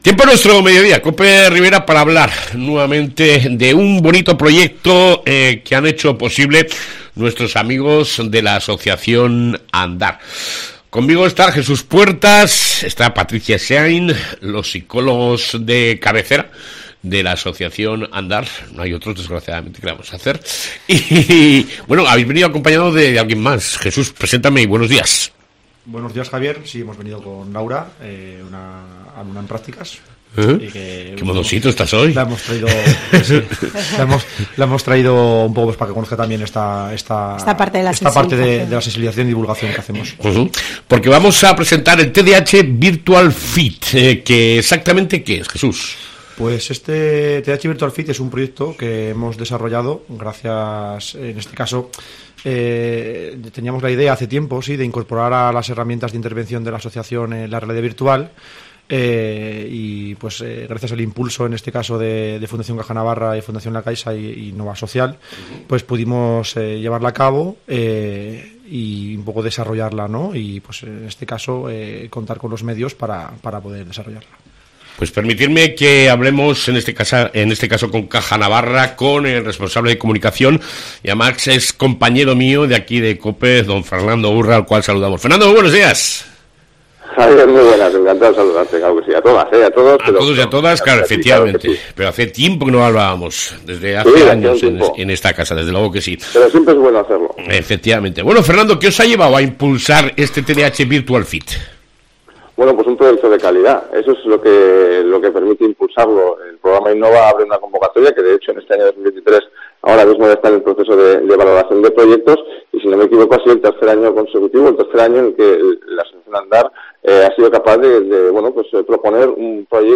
ENTREVISTA CON LA ASOCIACIÓN ANDAR